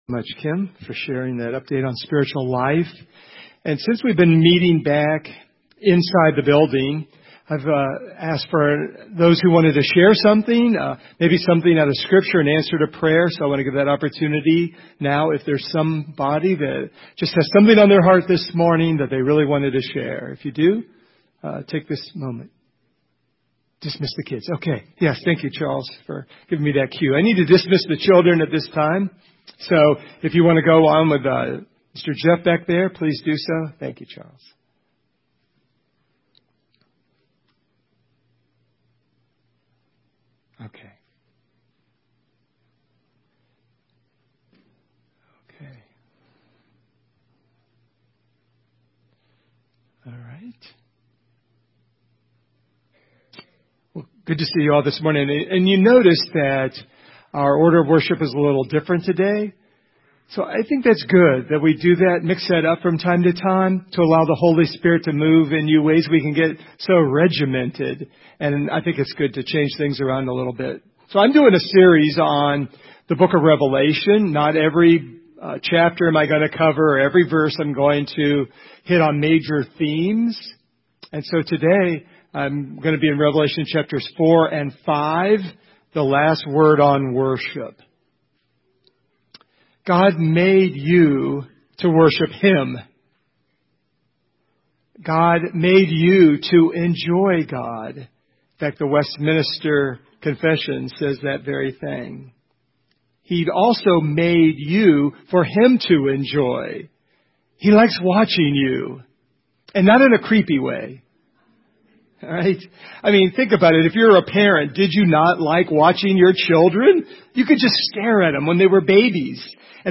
This sermon centers on God's throne and Jesus as the Lamb who alone is worthy.
Revelation 4 & 5 Service Type: Sunday Morning This sermon centers on God's throne and Jesus as the Lamb who alone is worthy.